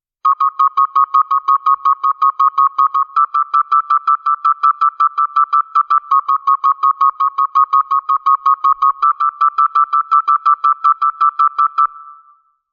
場面転換・オープニング・エンディング
急げ急げ